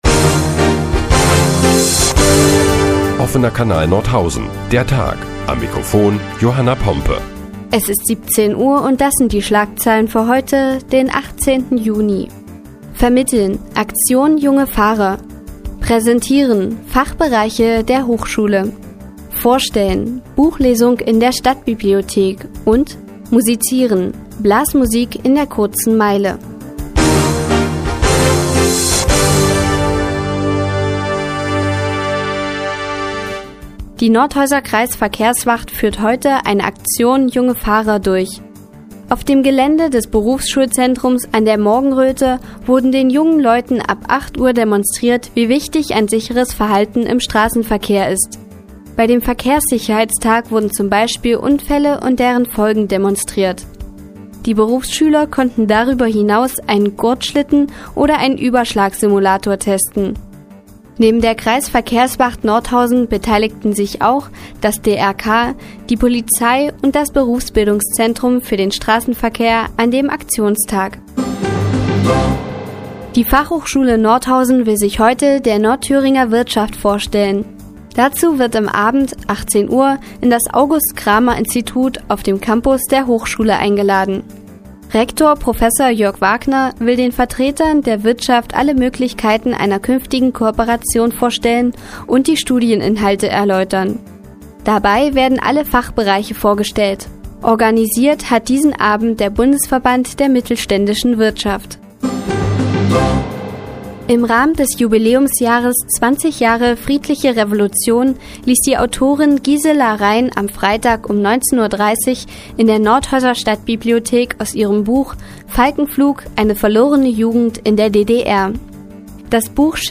Die tägliche Nachrichtensendung des OKN ist nun auch in der nnz zu hören. Heute geht es unter anderem um eine Buchvorstellung in der Stadtbibliothek und Blasmusik in der Kurzen Meile.